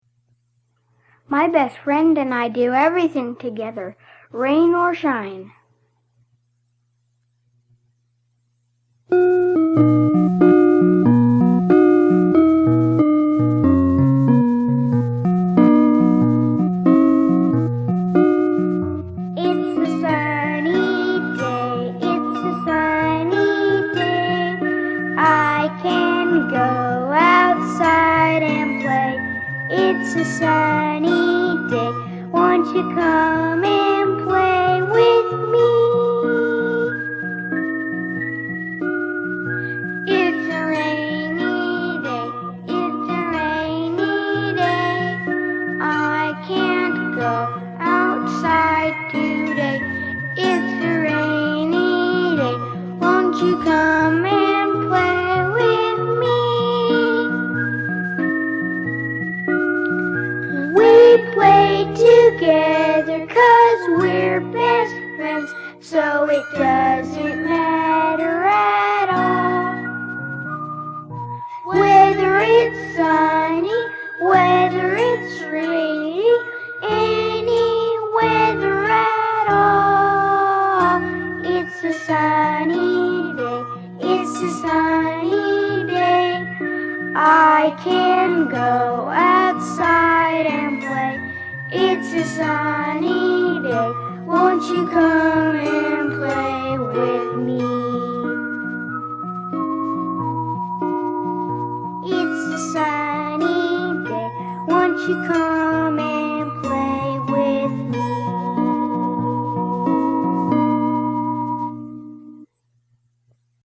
These are children songs for fun and learning.